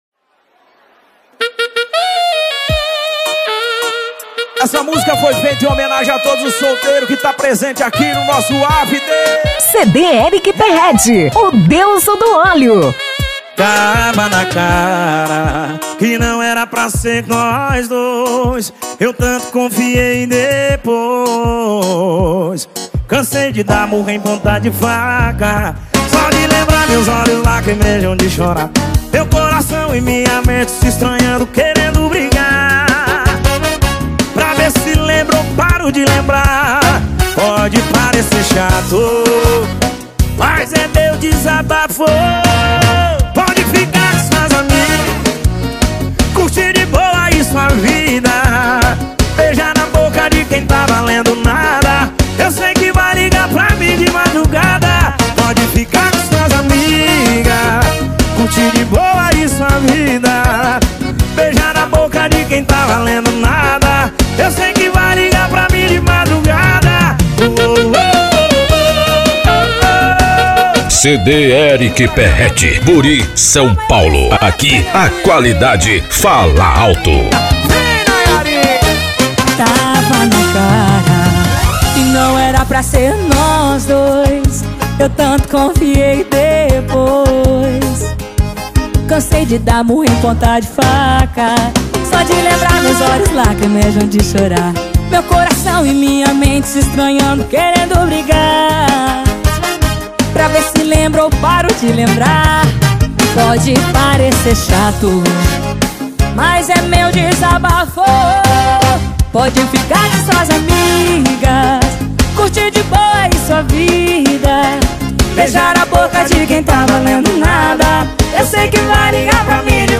Sertanejo Universitário